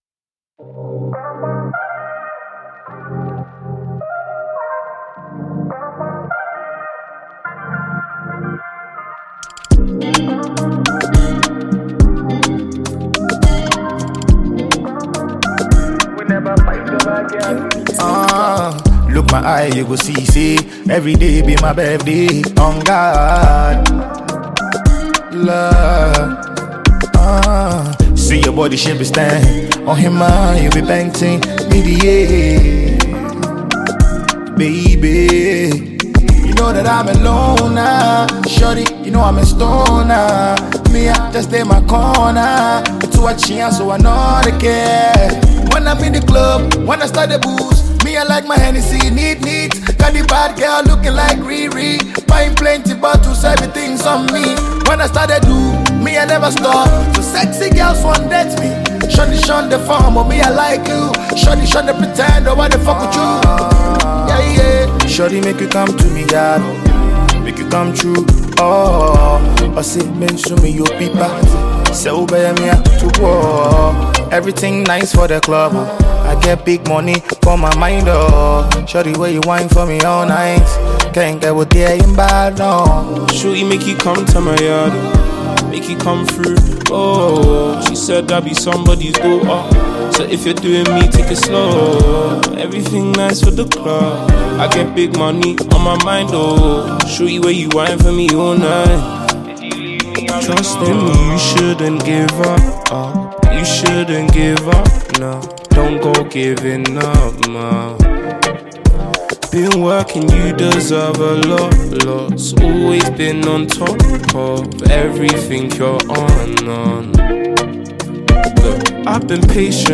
Ghanaian Afropop/Afrobeat singer and songwriter